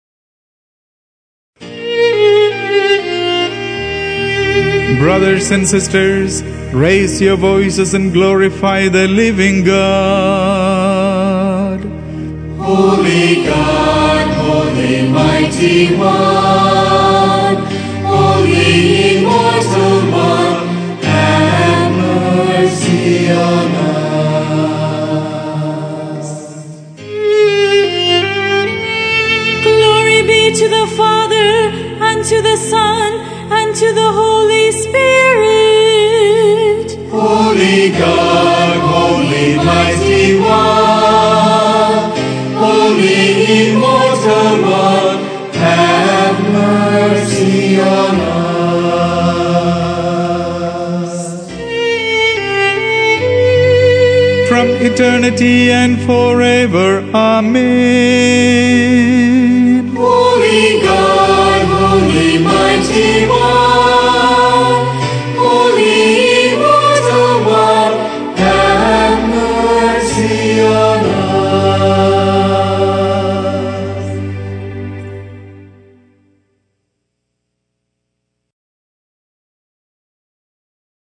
Category Liturgical
Performance space Church